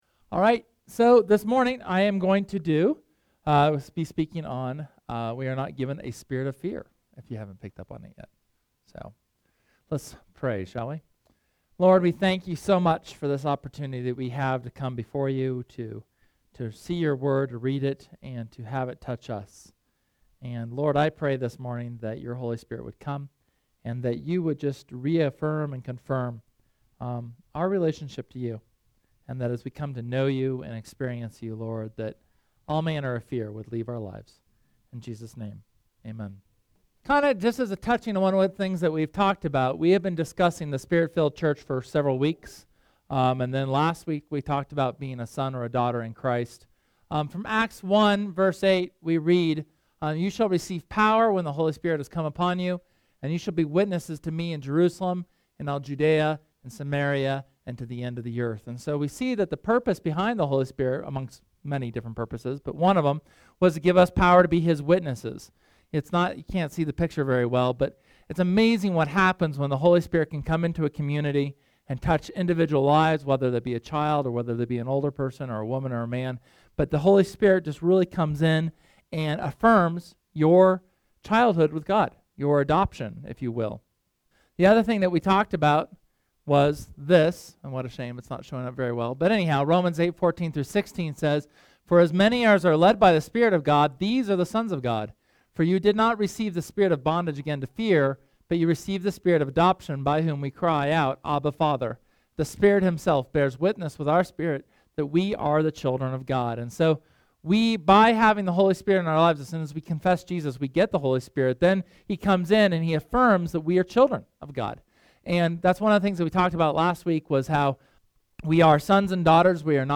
SERMON: We are not given a spirit of fear – Church of the Resurrection